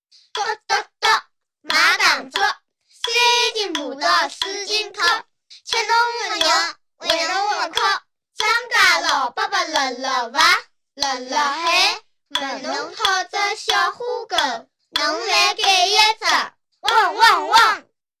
环境音